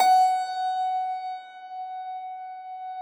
53k-pno16-F3.aif